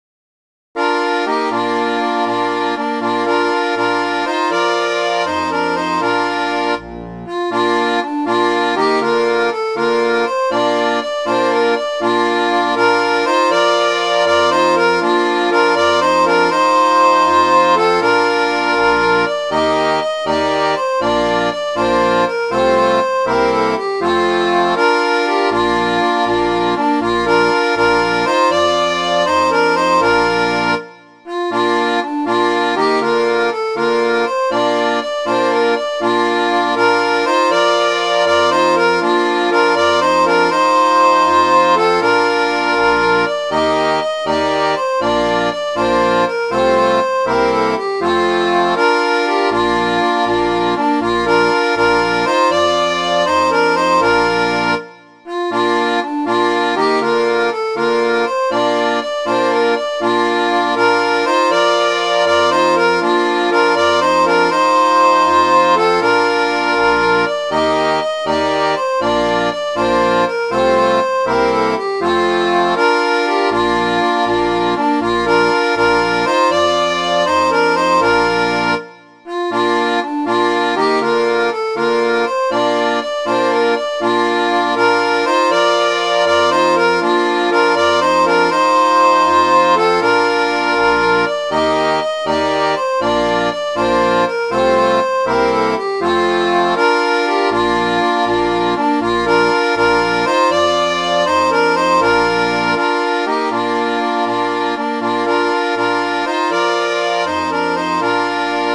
Midi File, Lyrics and Information to We're All Bound To Go
Frank Shay says this is a capstan shanty, but Stan Hugill gives it as a brake-windlass shanty.